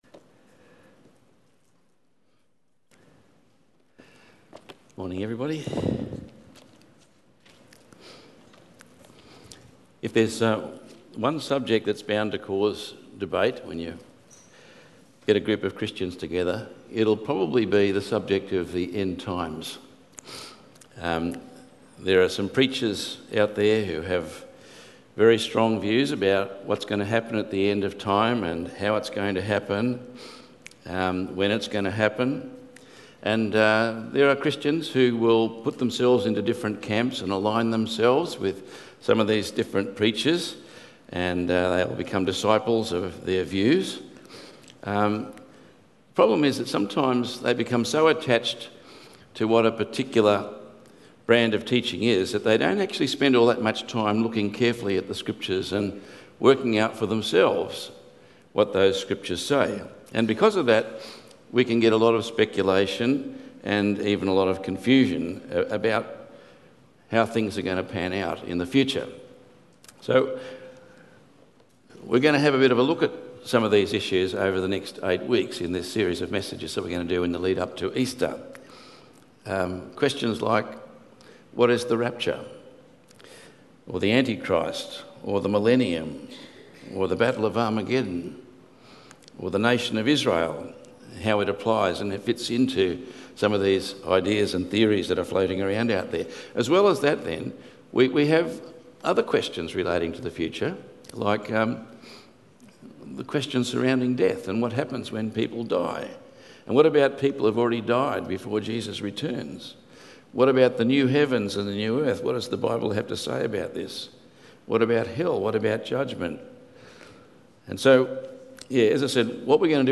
Tagged with Sunday Morning